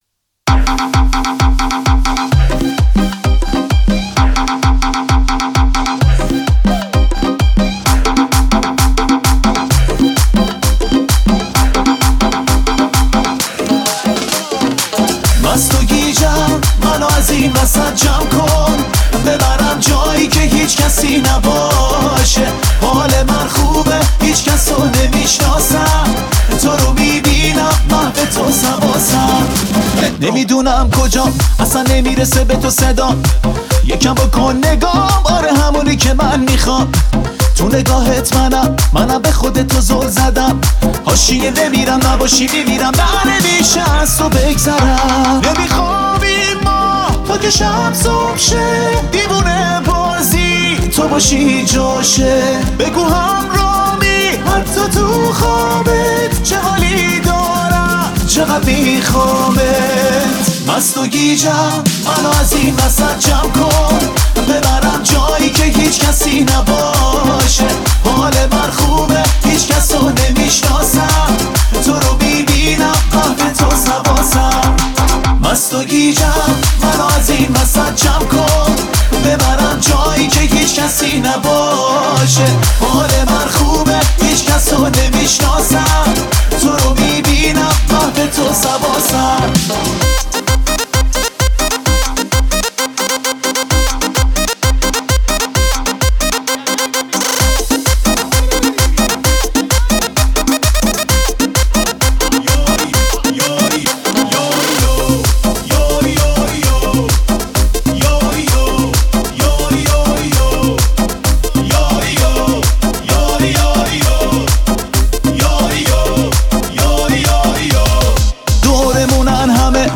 ریمیکس بیس دار